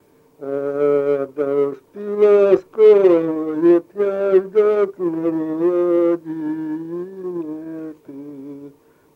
Отличные от нормативных окончания в именительном падеже единственного числа мужского рода прилагательных – безударная флексия –ый в соответствии с литературной -ой
/а-у”ж-ты гд’е” же-в’е”д’ коо-н’оо”-кот са-ма-лууу”-чшой у-т’а/